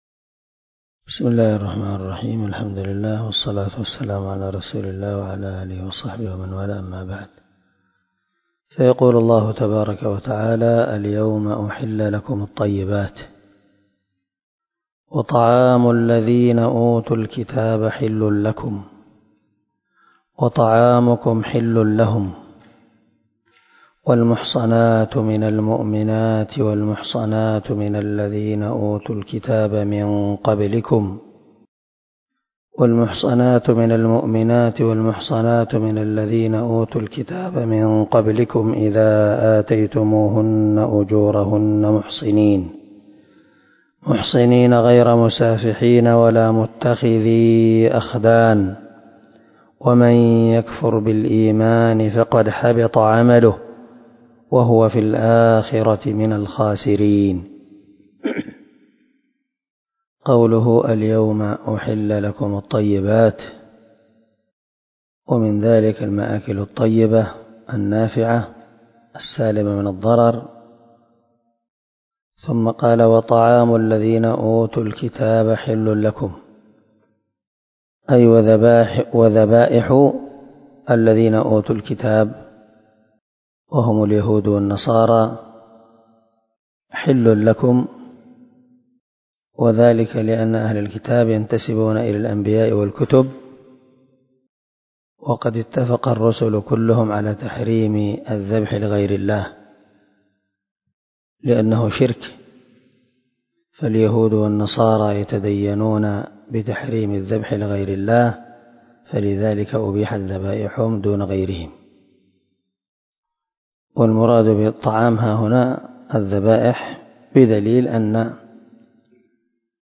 340الدرس 7 تفسير آية ( 5 ) من سورة المائدة من تفسير القران الكريم مع قراءة لتفسير السعدي
دار الحديث- المَحاوِلة- الصبيحة.